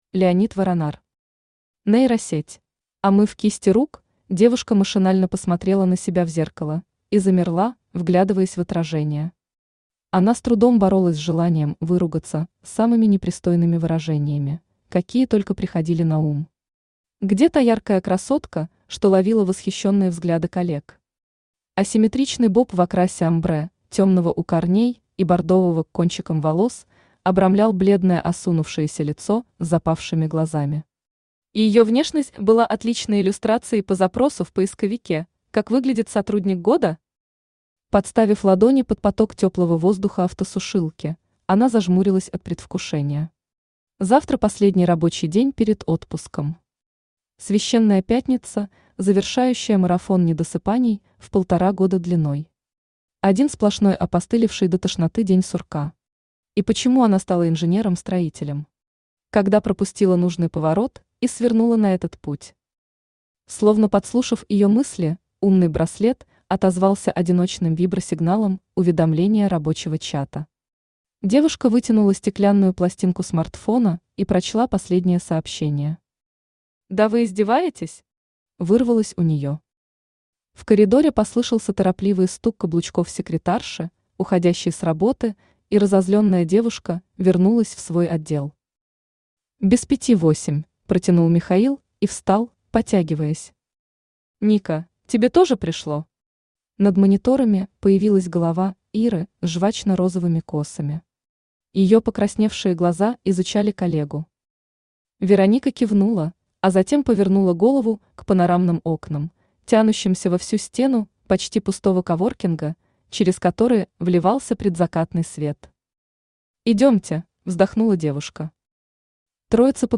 Аудиокнига Нейросеть | Библиотека аудиокниг
Aудиокнига Нейросеть Автор Леонид Воронар Читает аудиокнигу Авточтец ЛитРес.